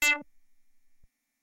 标签： MIDI-速度-32 D4 MIDI音符-62 挡泥板-色度北极星 合成器 单票据 多重采样
声道立体声